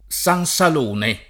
[ S an S al 1 ne ]